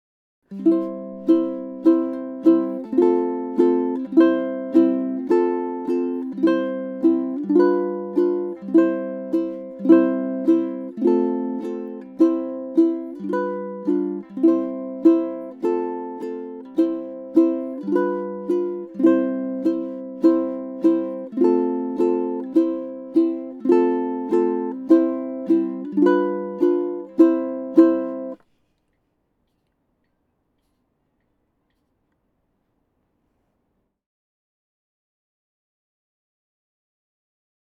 Flat-Four Strum | ʻImo ʻImo chords strummed four times per measure (no vamp).
When played well, each down strum sounds rich and fat, imparting an old timey jazz feel: chomp-chomp-chomp-chomp.